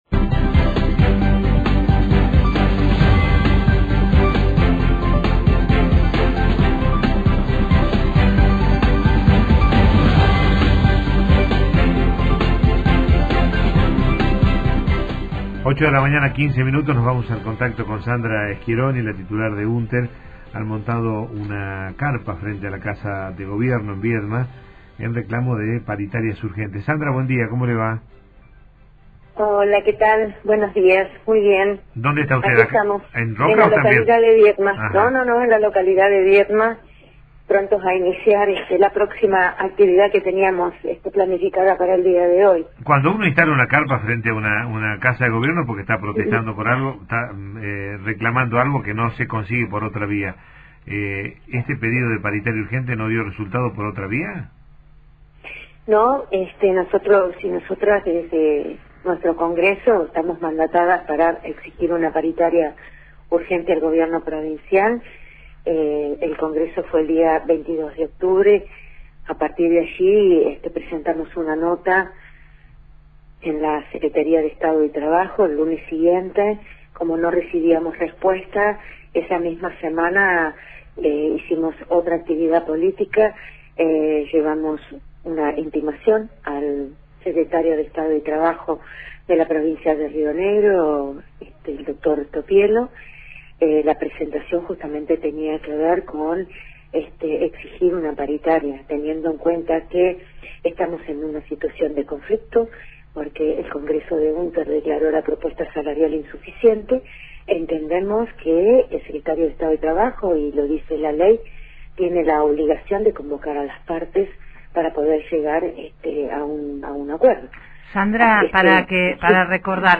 Entrevistas radiofónica